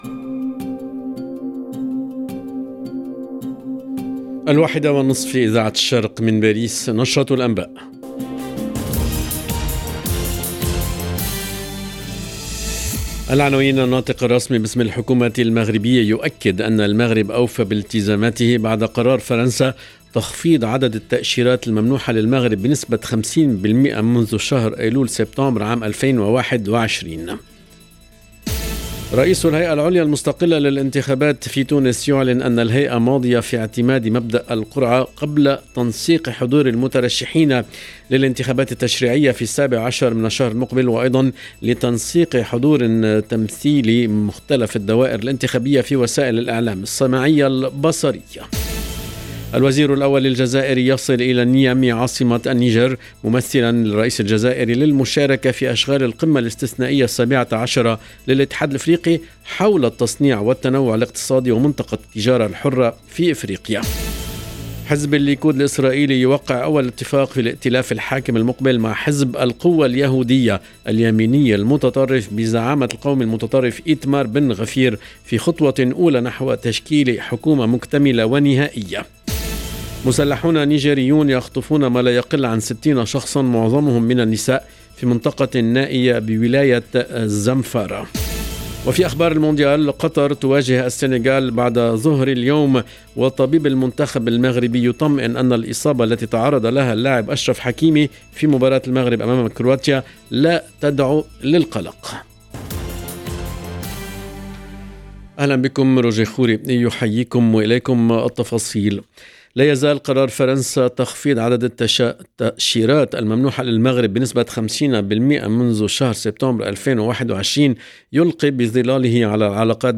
LE JOURNAL EN LANGUE ARABE DE 13H30 DU 25/11/22